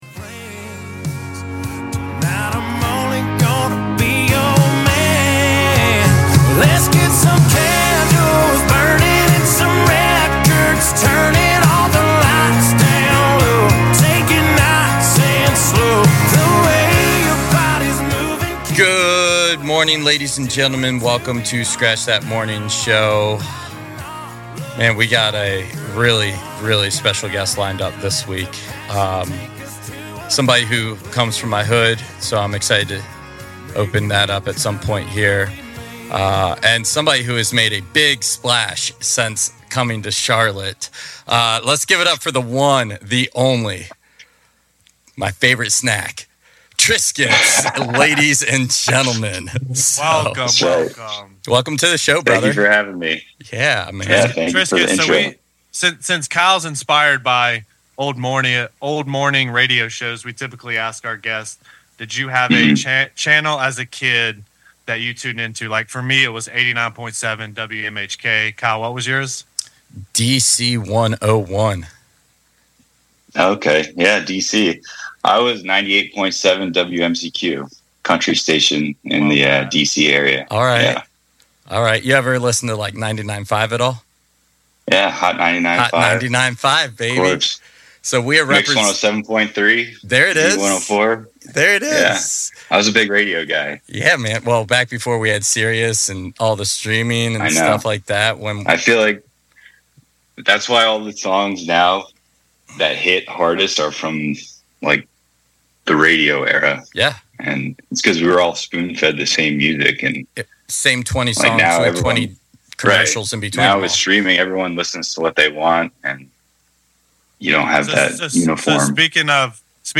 Welcome to Scratch That, a tribute to the old school talk morning show. Where we dish the headlines of DJing, music production, artist drama, and entertainment news.